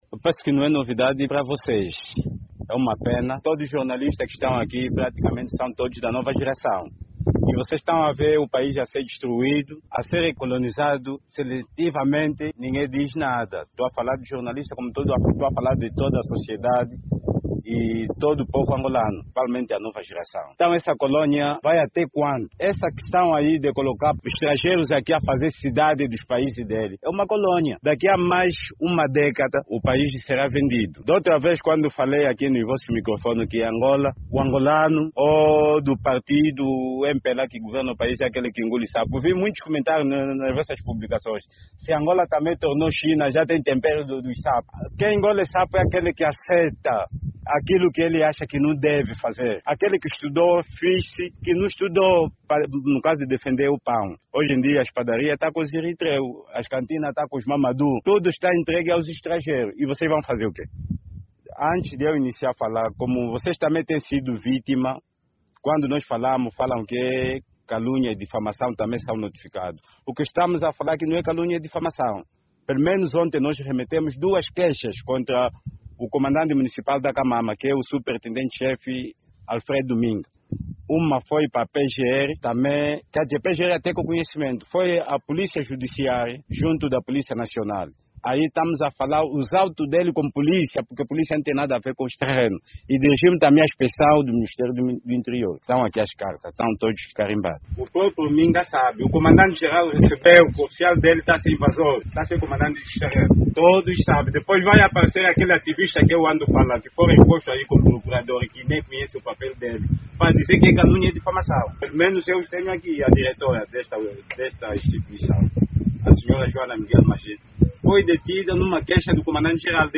Em entrevista aos jornalistas, os camponeses da empresa Konda Marta denunciaram o agravamento da tensão no terreno localizado nas proximidades do Estádio 11 de Novembro, em Luanda.